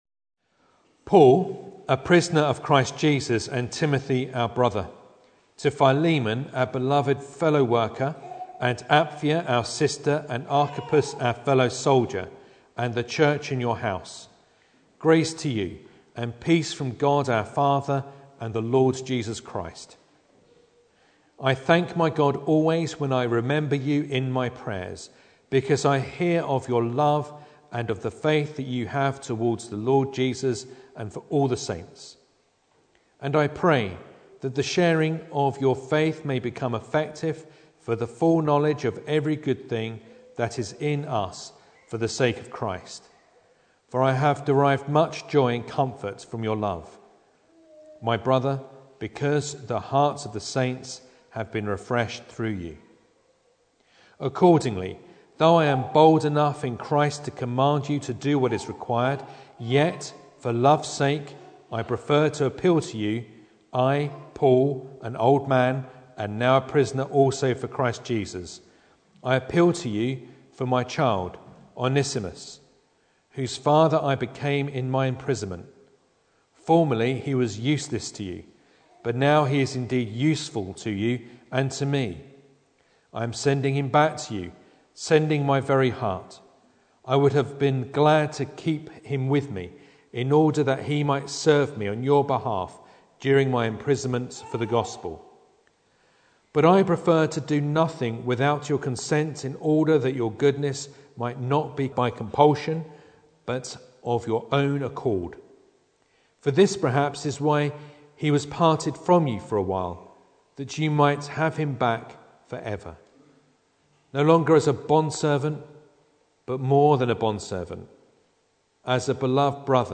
Philemon Service Type: Sunday Evening Bible Text